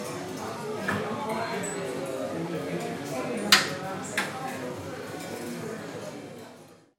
Coffee Shop
Warm coffee shop interior with espresso machine hiss, quiet conversation, and clinking cups
coffee-shop.mp3